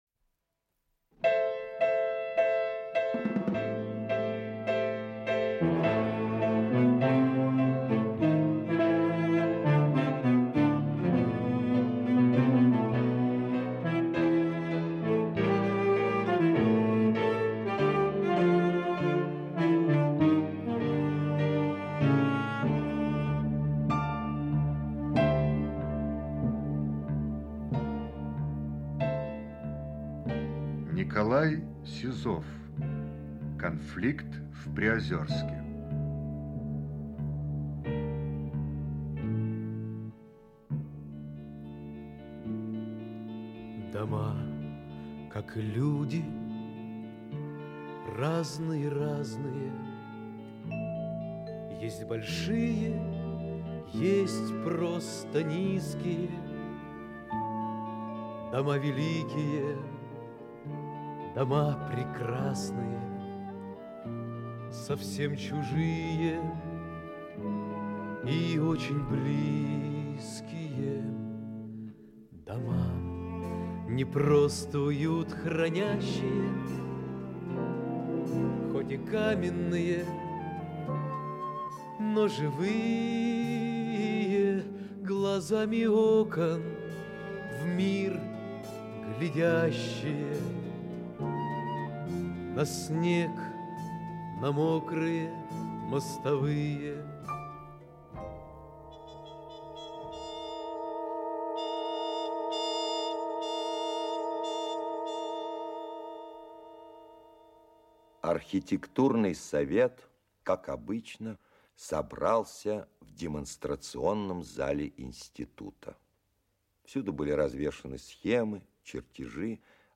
Часть 2 Автор Николай Трофимович Сизов Читает аудиокнигу Олег Табаков.